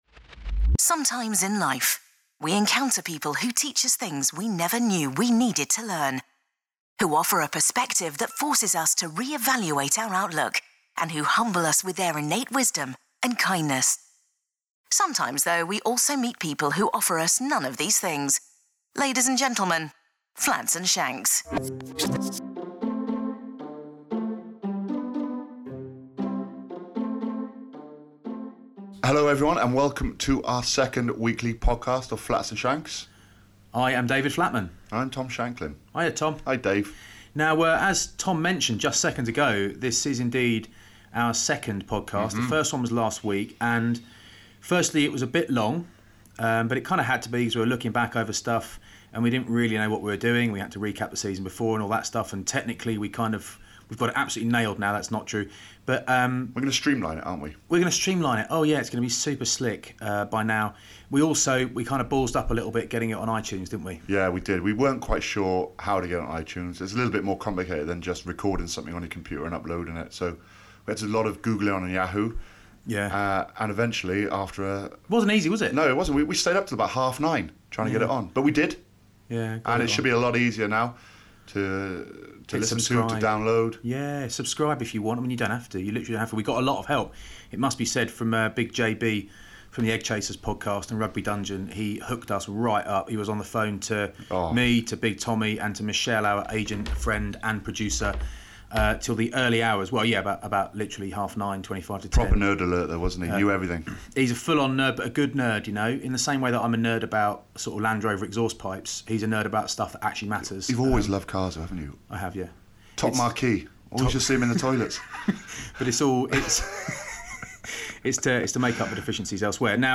2 great guys who shave their heads through choice chatting red cards, referring decisions and the weekends rugby action. Also an insight into how legends scrum with sand shoes on from Jimmy Gopperth.